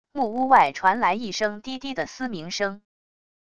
木屋外传来一声低低的嘶鸣声wav音频